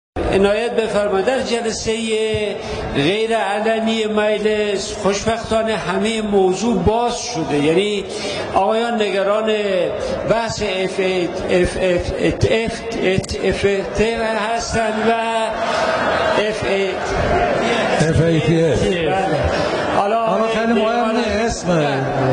• تلفظ نادرست یک کلمه مانند؛ (مُگُم-می گویم) ، (قُلف- قُفل) ، (مُکشِل-مُشکِل) و حتی FATF